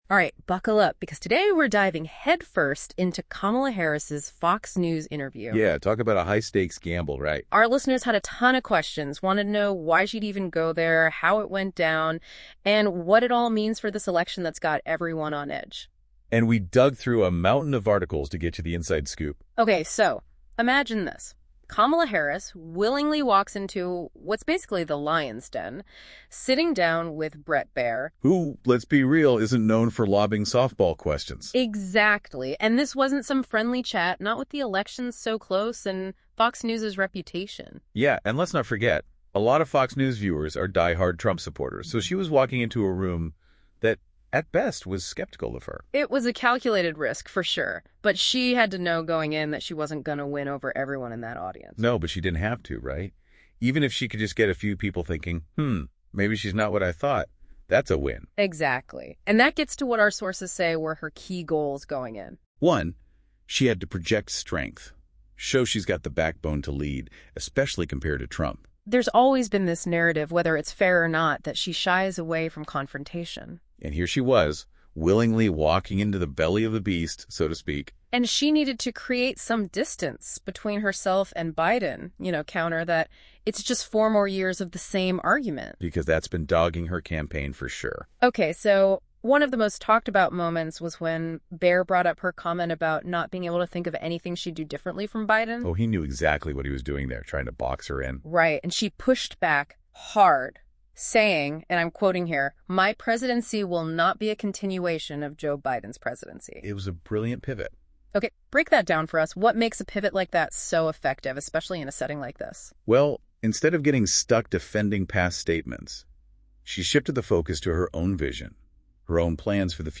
Listen to a deep dive conversation about the interview.